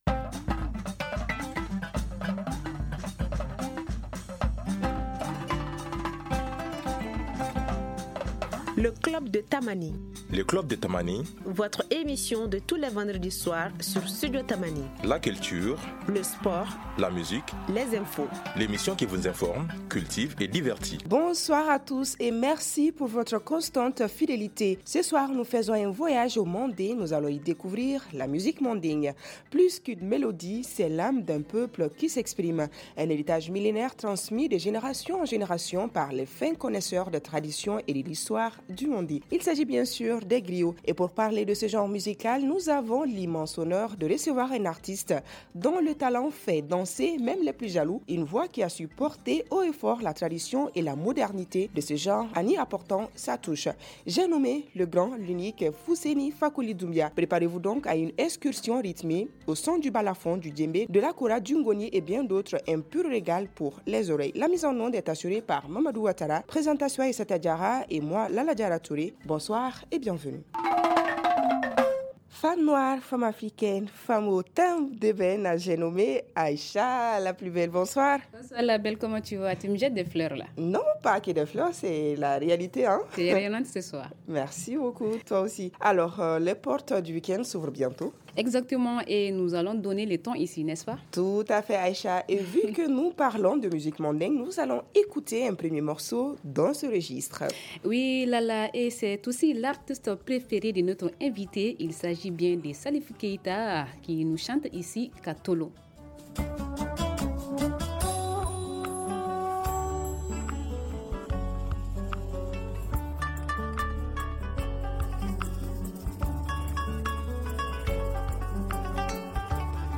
Plus qu’une mélodie, c’est l’âme d’un peuple qui s’exprime. Un héritage millénaire, transmis de génération en génération, par les fins connaisseurs des traditions et de l’histoire du Mandé. Et pour parler de ce genre musical, nous avons l’immense honneur de recevoir un artiste dont le talent fait danser même les plus jaloux.